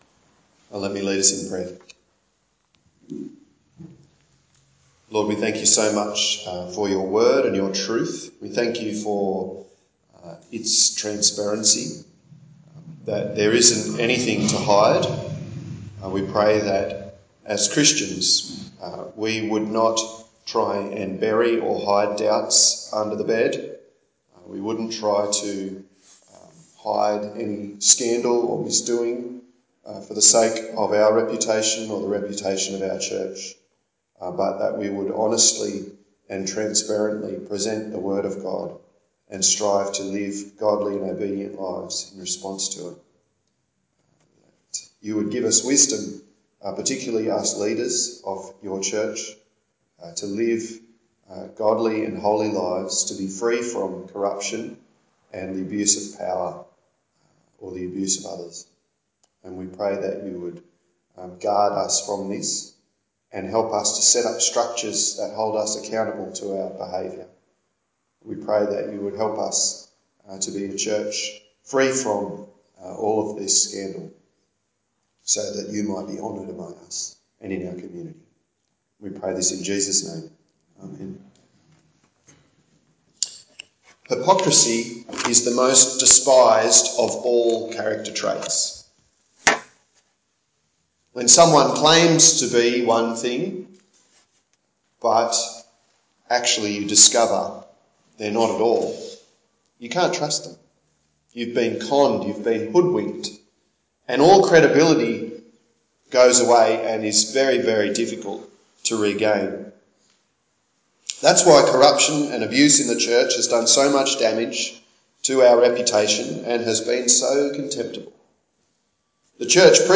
30/04/2017 Corruption and Abuse in the Church Preacher